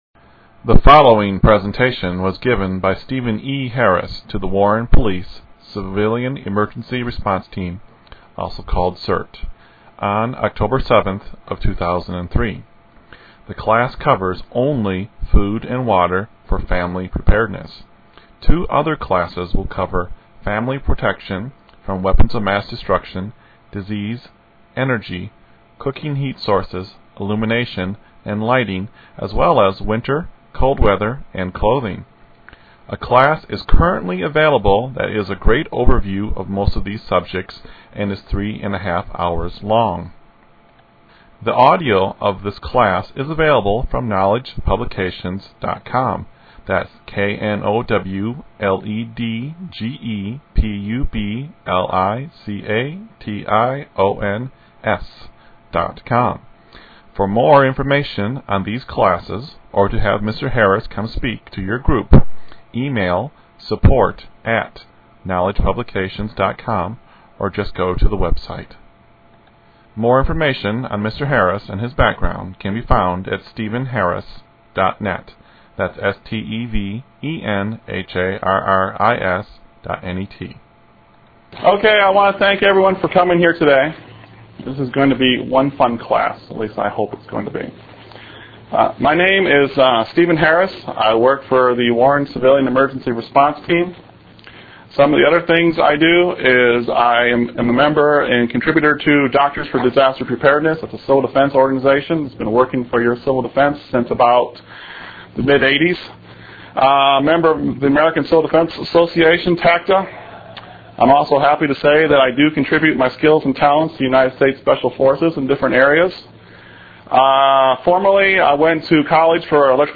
This class was given to a room of 30+ people and it covers the methods, principles and items used for the protection of a family in time of a crisis. This includes a biological or chemical crisis, natural disaster and more. The class is very engaging, they ask many questions and it gets to be quite funny at times.